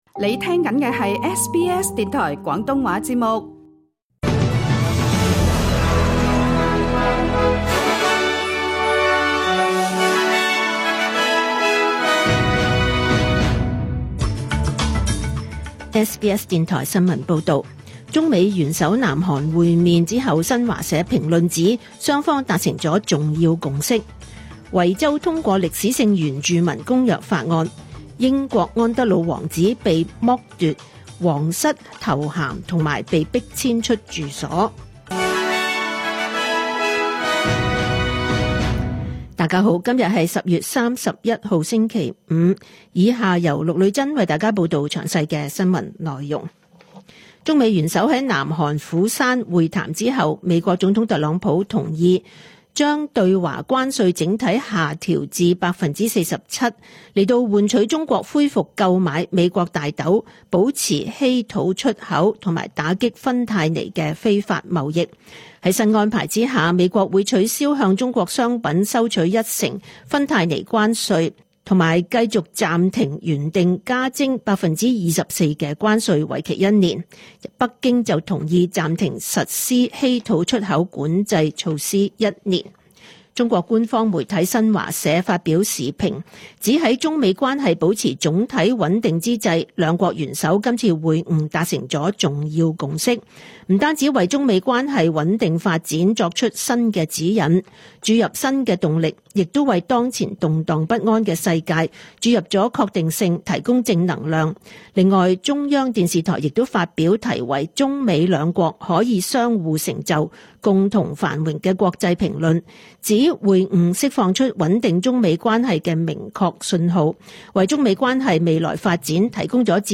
2025 年 10 月 31 日 SBS 廣東話節目詳盡早晨新聞報道。